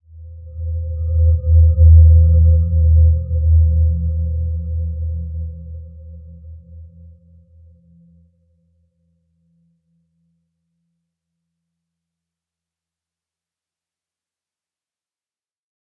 Dreamy-Fifths-E2-mf.wav